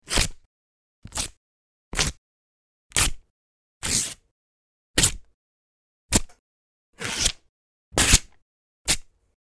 Paper Tear/Rip SFX (No Copyright) | Royalty Free Sound Effects
10 copyright free paper rip/tear sound effects. High quality paper rip/tear sound effects; free to use!
I made these paper tear (or paper rip) sound effects myself by just tearing a few sheets of paper.
paper-tear-sfx.mp3